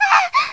SFXv配音-女惨叫-轻-002音效下载
SFX音效